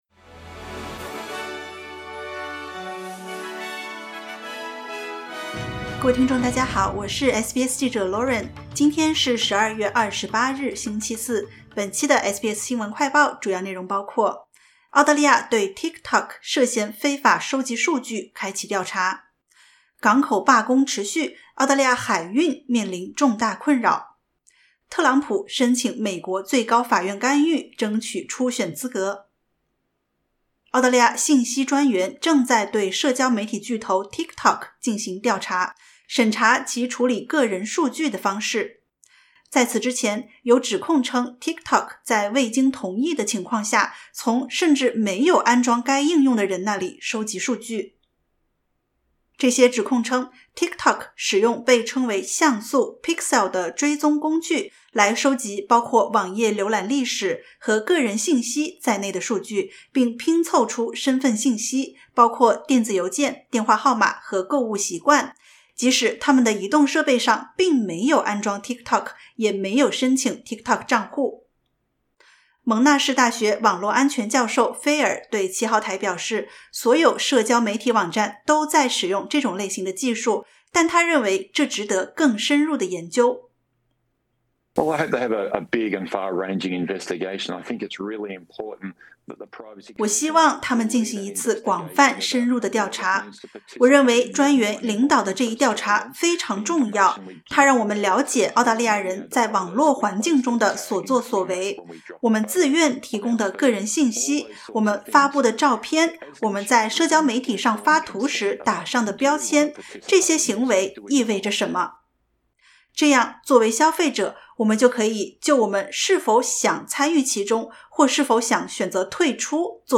【SBS新闻快报】澳大利亚对TikTok涉嫌非法收集数据开启调查